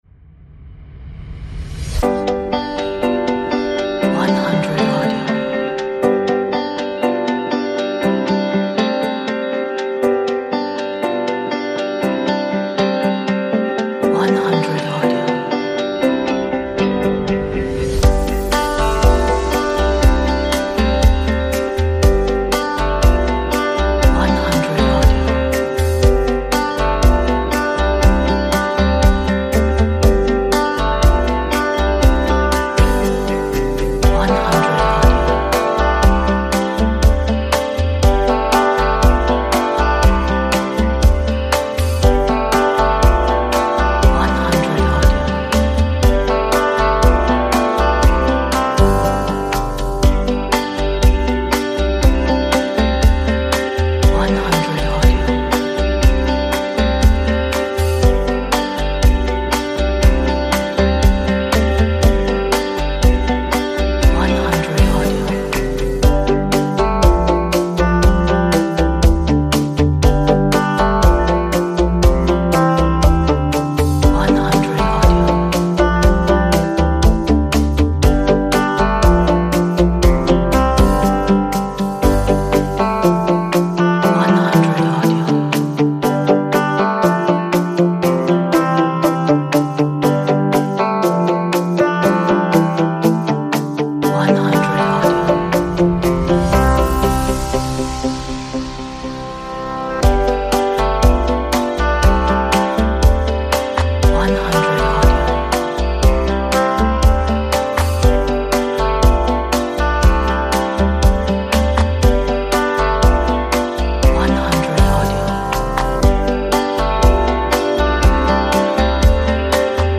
An inspirational and motivational background track.